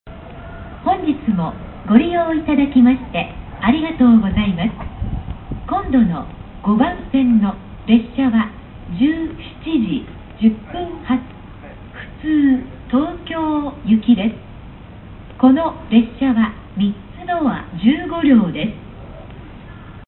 次発放送普通東京行き3ドア15両 次発放送です。